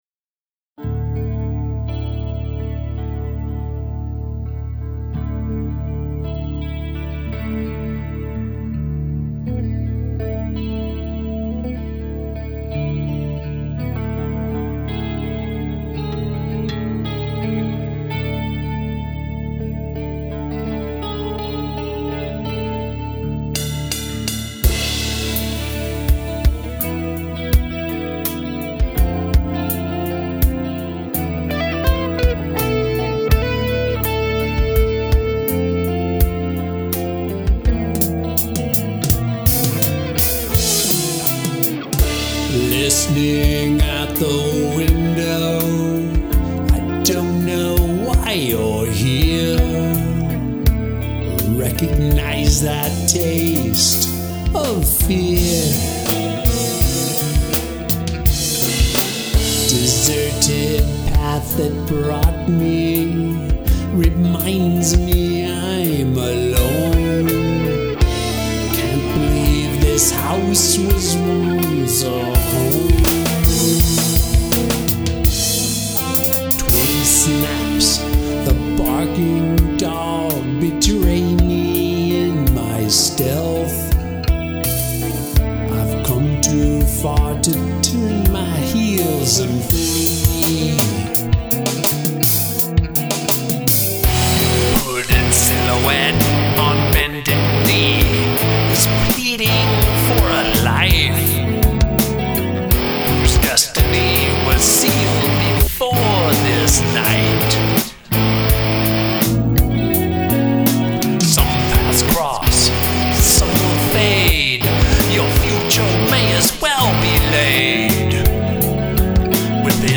This one is a bit dark.